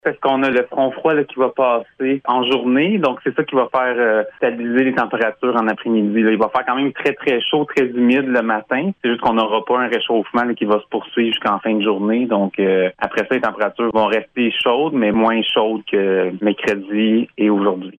La chaleur est très intense avec le mercure qui grimpe au-dessus de 40 degrés Celsius avec le facteur humidex, mais la canicule devrait cesser dans la journée de jeudi, comme le précise le météorologue :